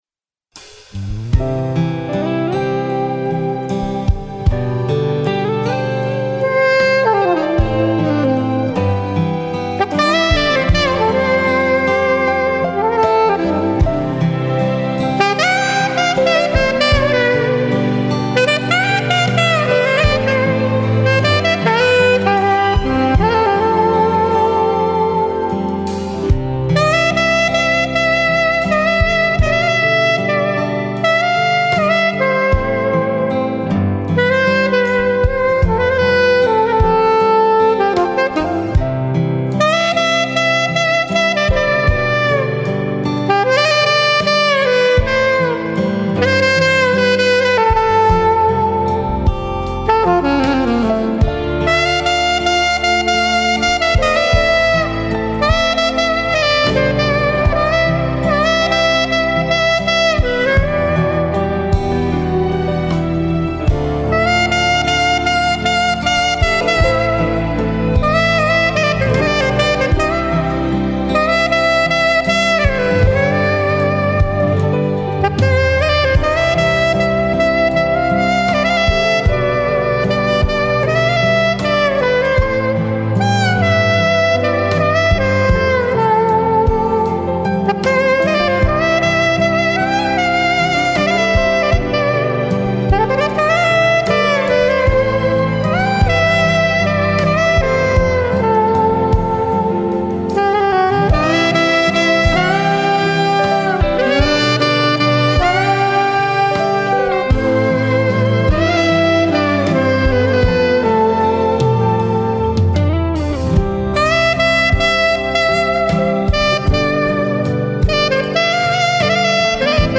Saxophone
popular praise and worship single